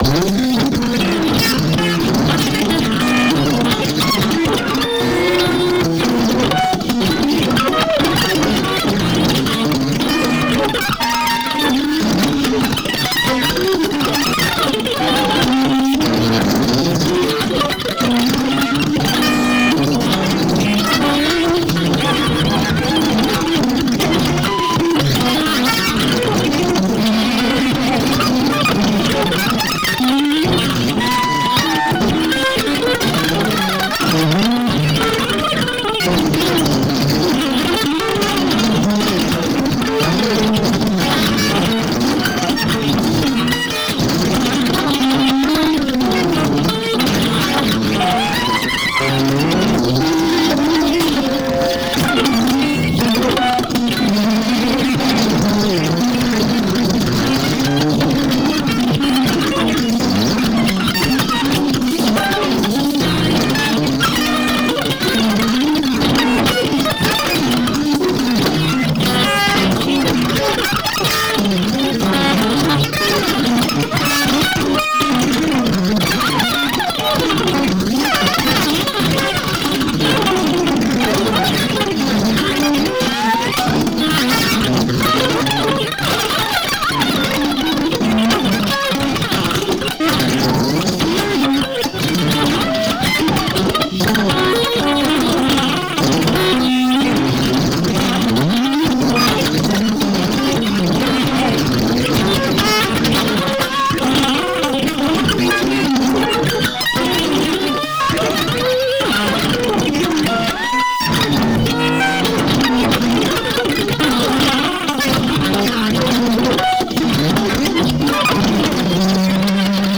両手の10本の指が、それぞれに独立した意思を持って、能弁に語り始めたような複雑な音です。
たいへんデリケートで複雑なパンニングと、スペクトル分布を特徴とするアルバムです。
ギター愛好家の方々にはもちろん、現代音楽、先端的テクノ、実験音楽をお好きな方々にもお薦めのアルバムです。